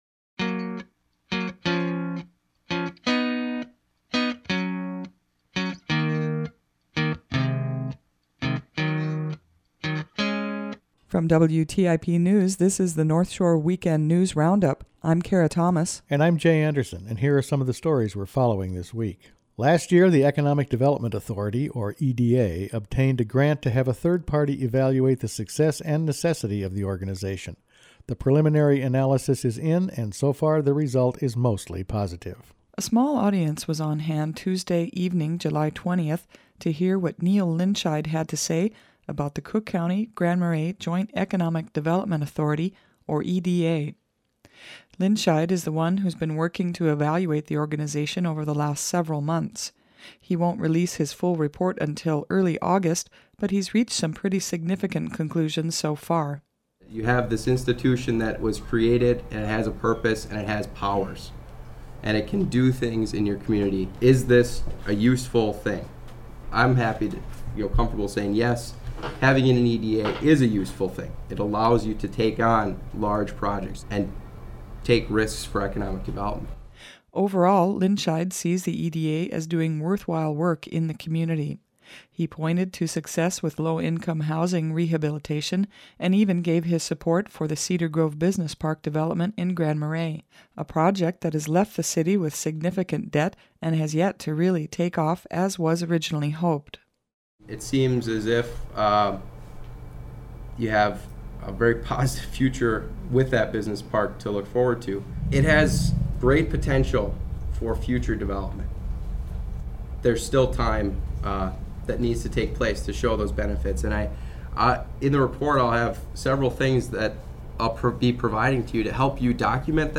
Each weekend the WTIP News Department assembles the week’s important news stories to play here on North Shore Weekend. Among other things, this week its been Veterans, the EDA, politics and carp.